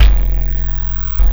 Kick Particle 04.wav